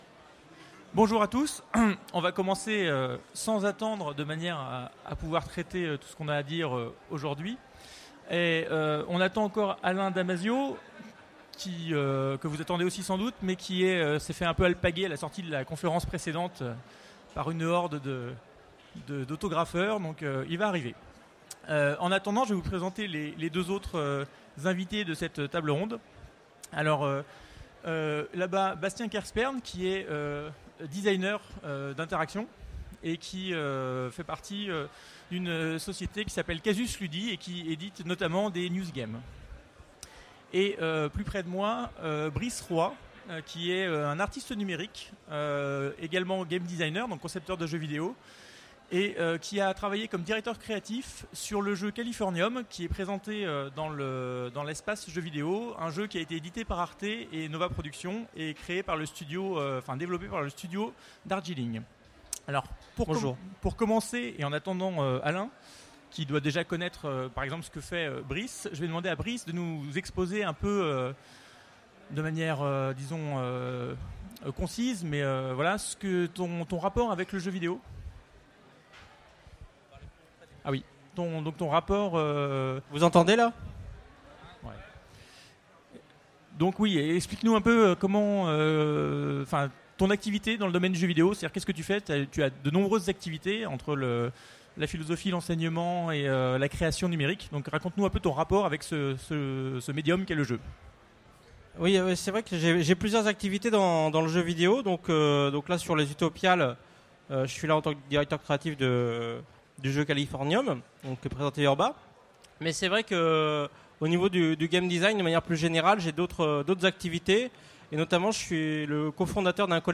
Utopiales 2015 : Conférence Le jeu vidéo vecteur de messages